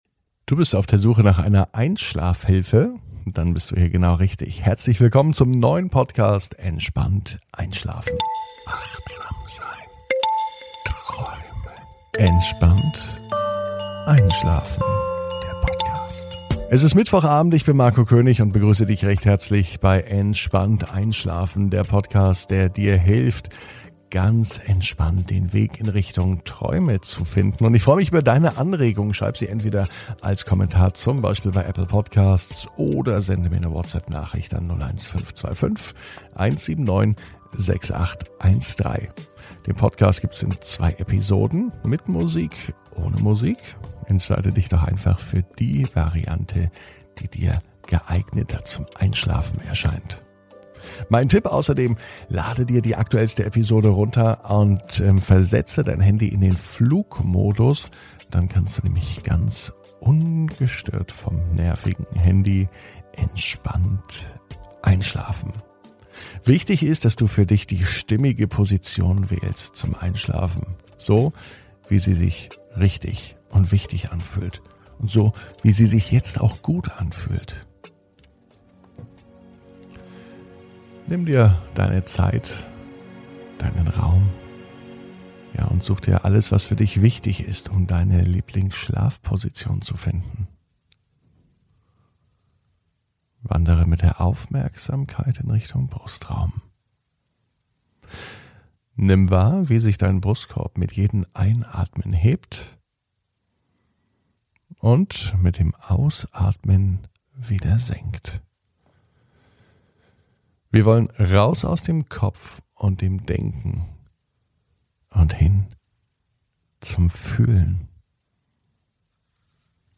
(Ohne Musik) Entspannt einschlafen am Mittwoch, 26.05.21 ~ Entspannt einschlafen - Meditation & Achtsamkeit für die Nacht Podcast